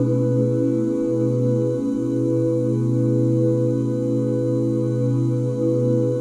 OOH B MIN9.wav